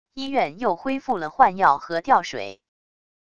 医院又恢复了换药和吊水wav音频